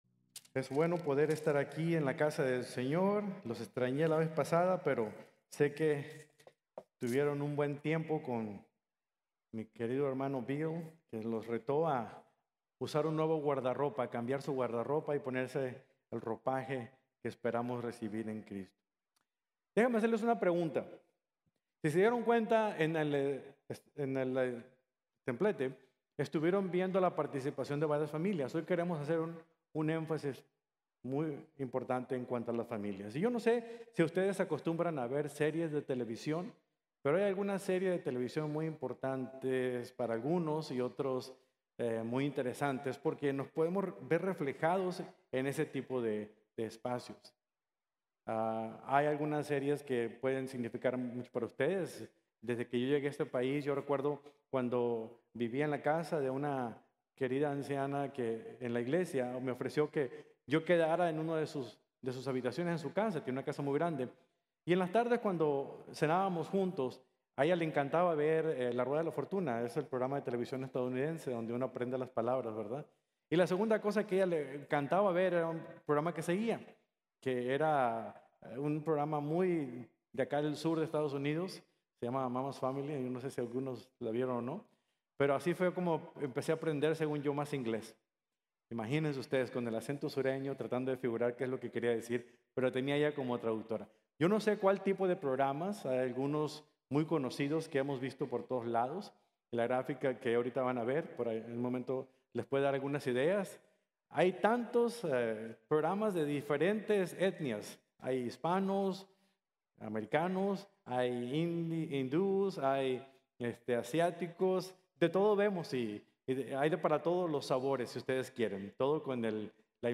Nuevas Reglas de la Casa | Sermon | Grace Bible Church